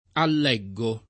eleggere [el$JJere] v.; eleggo [el$ggo], ‑gi — coniug. come leggere — nel ’200 e ’300, anche alleggere [all$JJere]: alleggo [